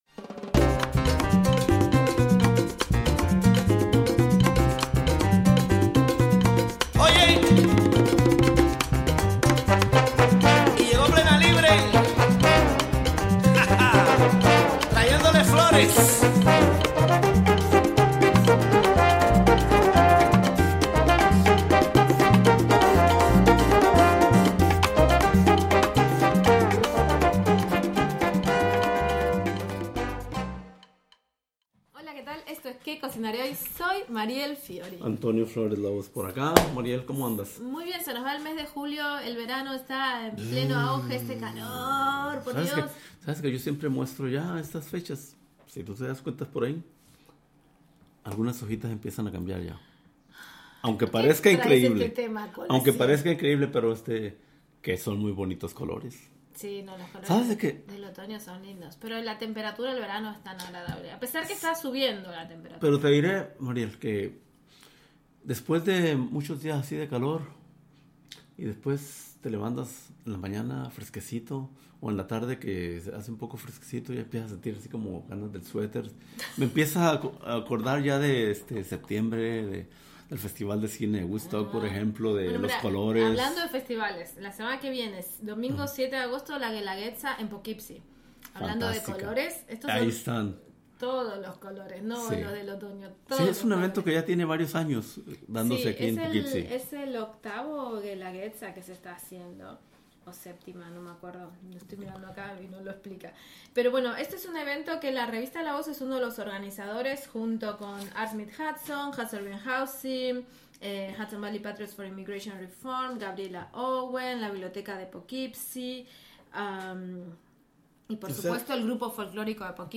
Weekly Spanish language radio news show featuring interviews, commentary, calendar of events and music. In this broadcast, a conversation about popular swimming spots in the Hudson Valley. In the broadcast, a conversation about the traditional Guelaguetza Festival to be held August 7 in Waryas Park in Poughkeepsie.